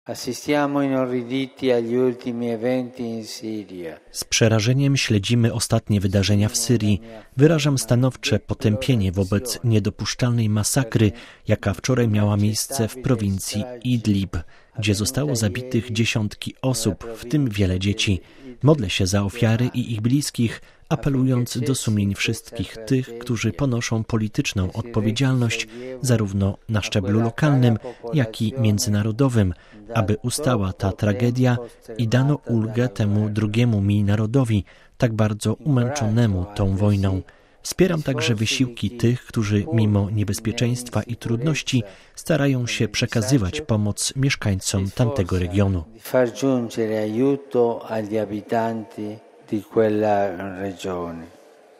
Na zakończenie środowej audiencji ogólnej Ojciec Święty nawiązał do zamachu w metrze w Petersburgu i zapewnił o modlitwie za jego ofiary oraz o duchowej bliskości z ich bliskimi.